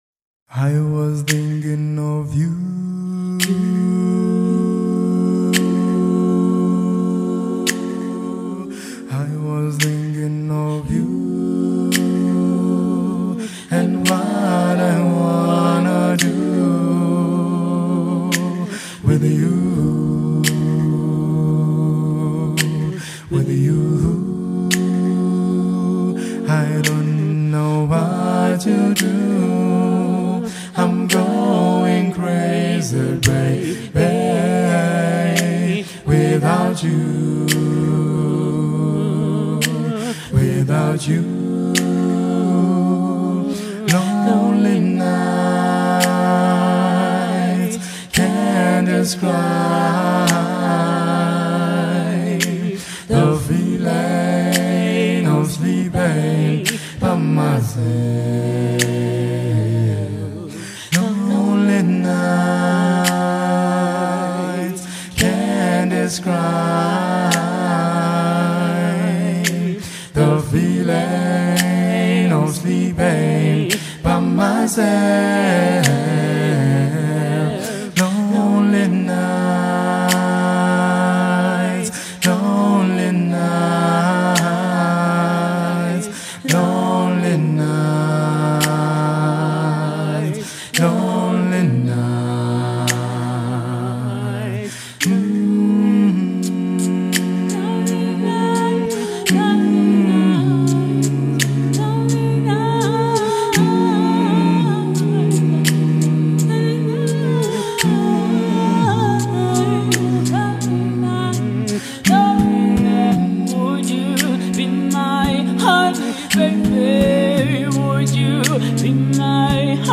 South African acapella group